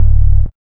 3508R BASS.wav